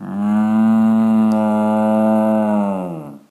cow-moo-3.ogg